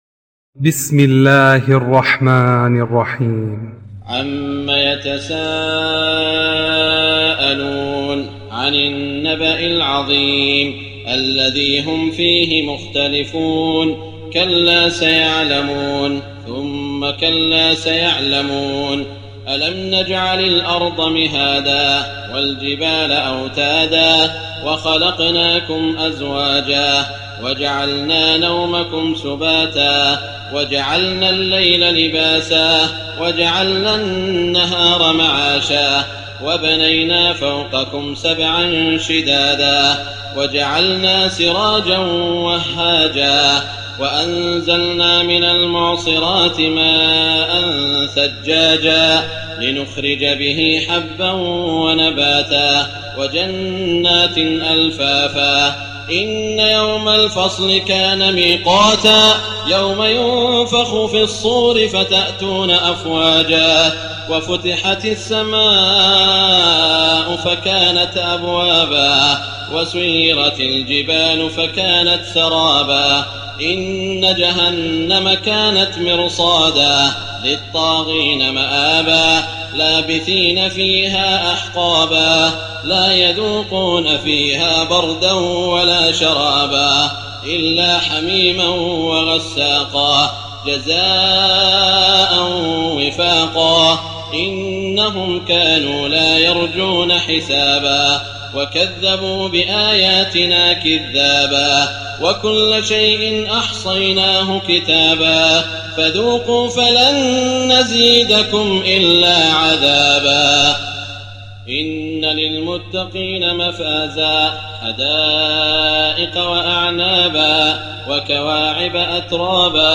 تراويح ليلة 29 رمضان 1419هـ من سورة النبأ الى الطارق Taraweeh 29 st night Ramadan 1419H from Surah An-Naba to At-Taariq > تراويح الحرم المكي عام 1419 🕋 > التراويح - تلاوات الحرمين